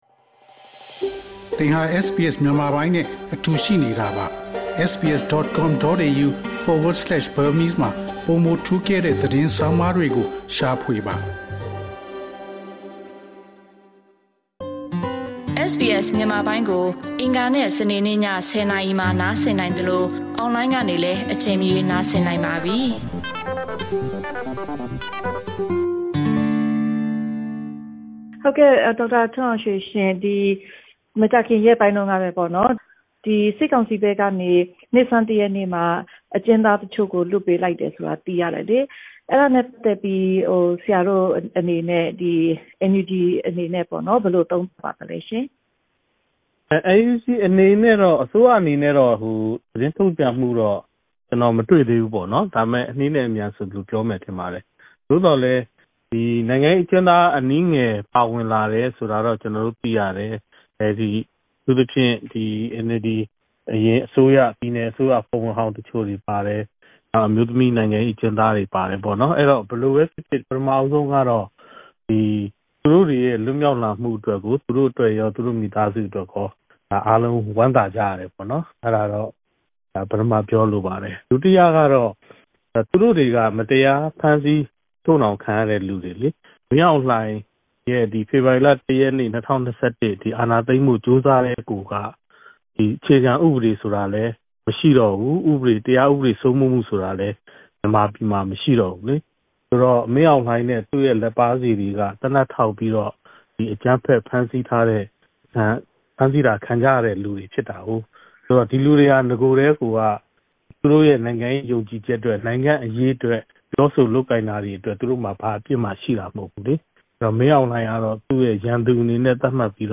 ဒေါက်တာထွန်းအောင်ရွှေကို လွတ်မြောက်လာတဲ့ မြန်မာအကျဉ်းသားတွေနဲ့ နဲ့ပတ်သက်လို့ မေးမြန်းထားခန်း။